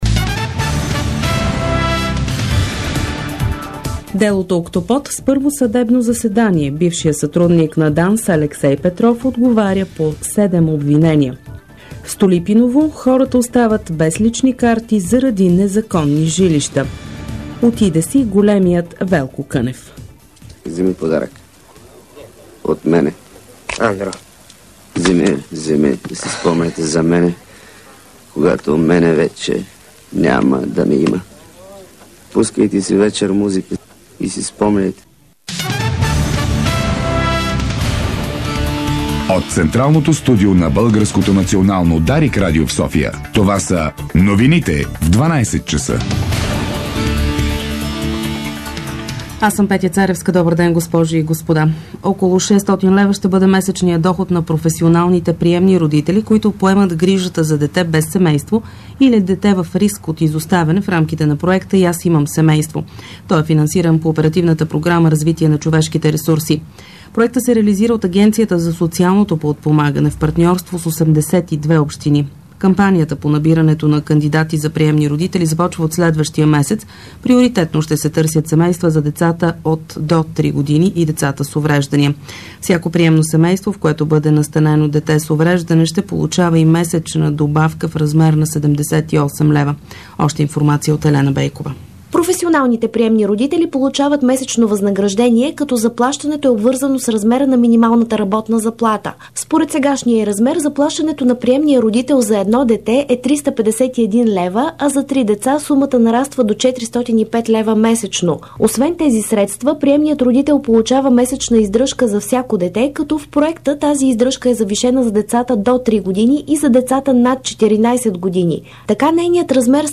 Обедна информационна емисия - 12.12.2011